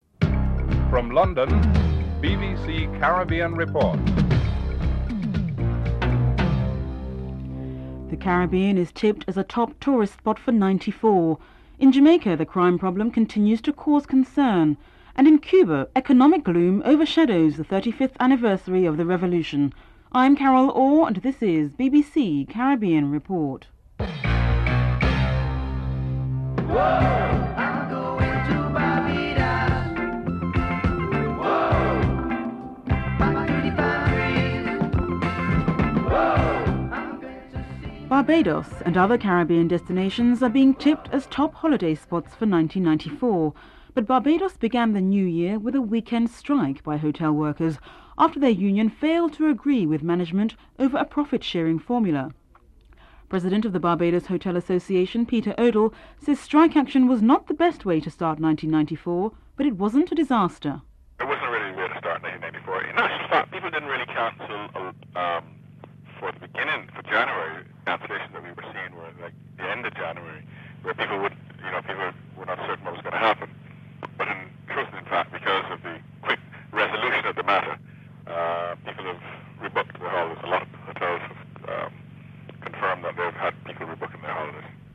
Headlines (00:00-00:25)
At the beginning of the report, President Fidel Castro’s can be heard in the background giving a speech to commemorate the 35th Anniversary of the Cuban revolution.
Caribbean report theme (14:40- 14:50)